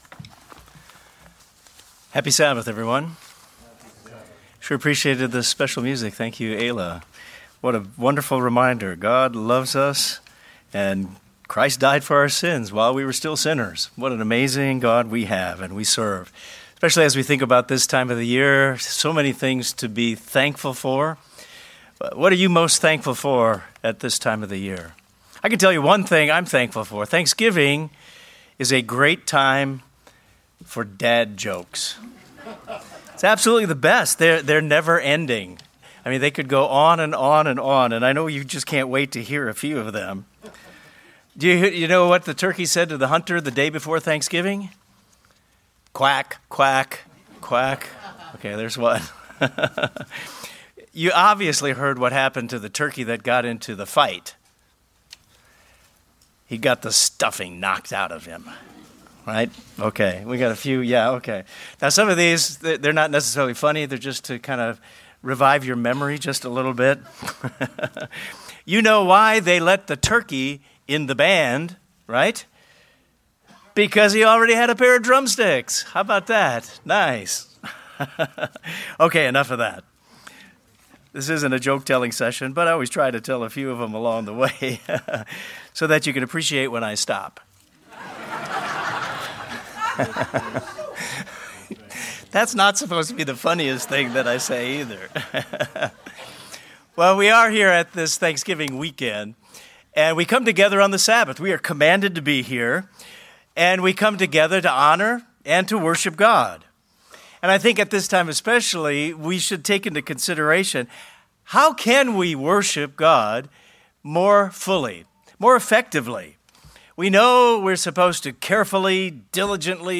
This sermon explores what true worship really is and challenges us to rethink how we worship in our lives.